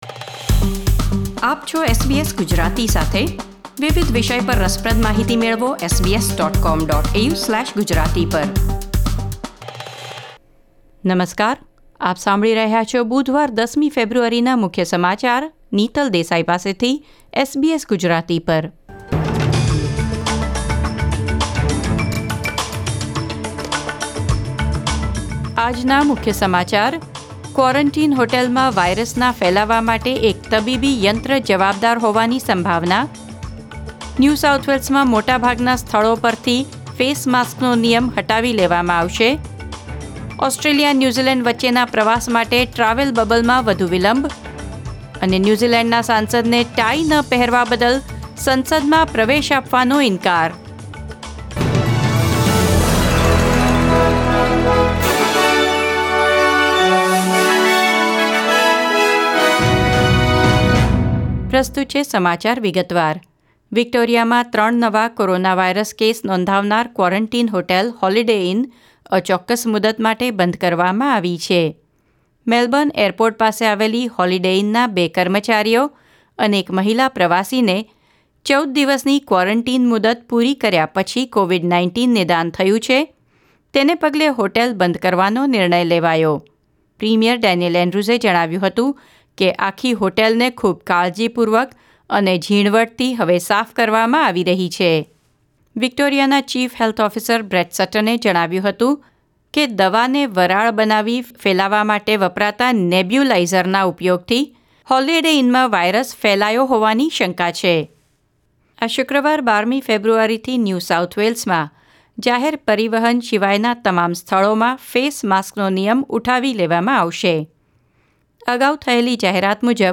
SBS Gujarati News Bulletin 10 February 2021